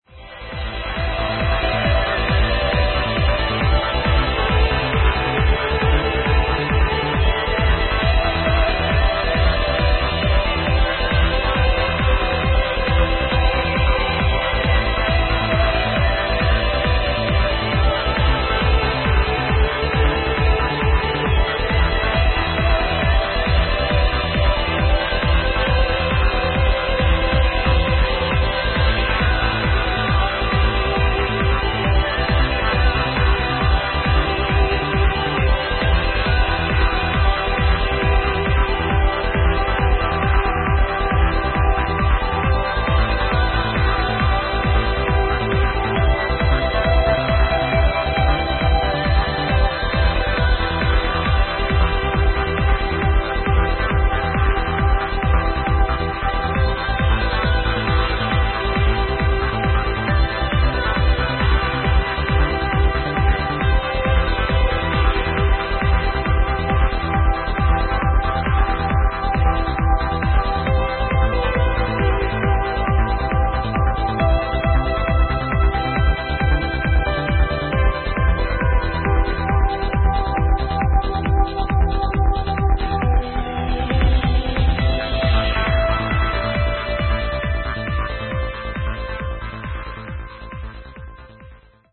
amasing flying trance id